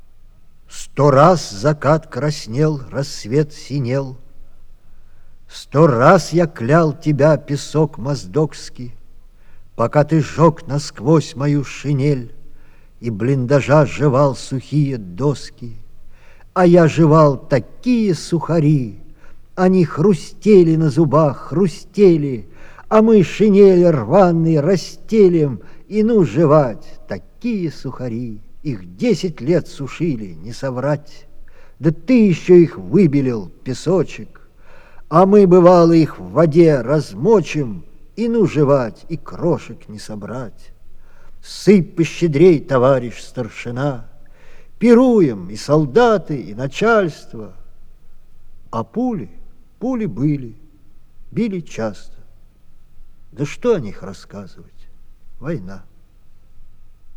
Многие песни уже звучали на Завалинке,но здесь подборка песен и стихов на одном диске в исполнении автора.